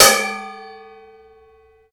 PRC BOWL H0B.wav